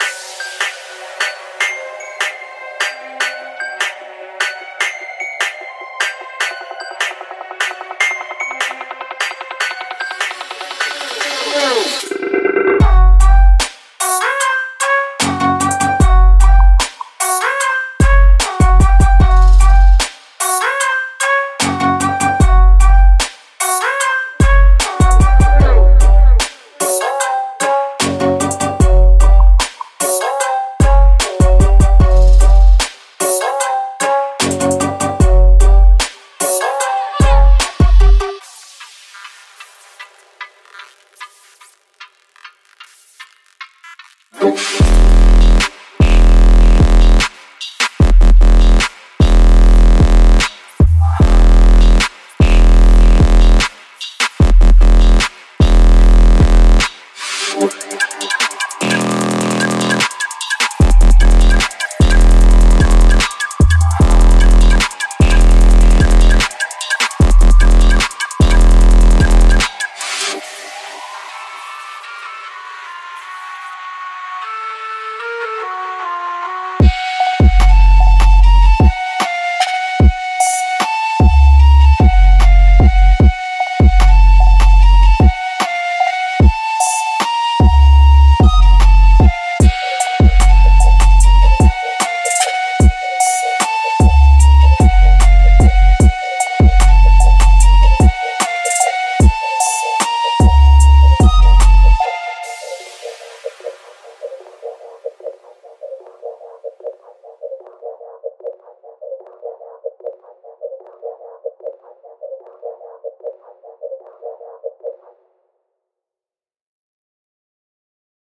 •48 Full Drum Loops, Kick&Snare Loops, Sub Loops, Top Loops
•31 Synth & Bass One Shots